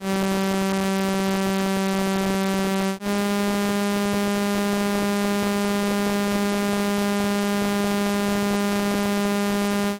Stimmtöne Arkadische Botschaften I mp3
micro_guitar3I.mp3